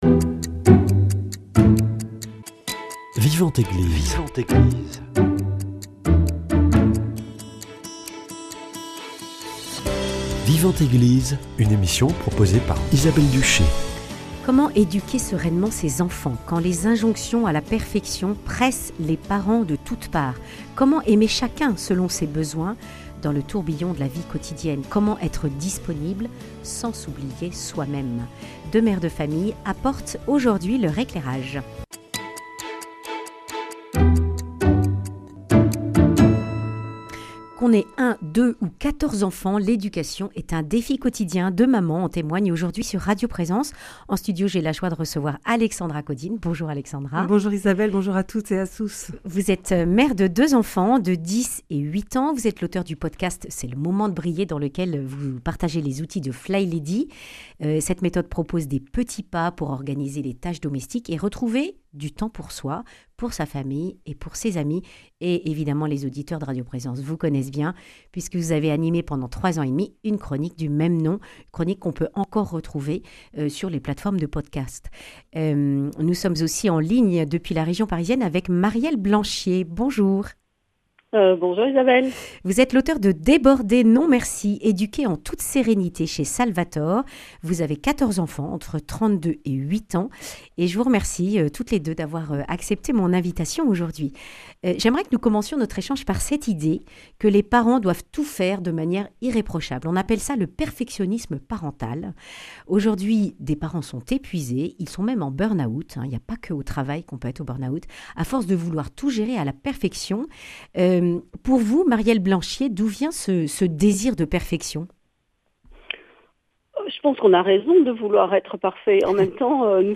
Deux mères de famille témoignent de leur place auprès de (…)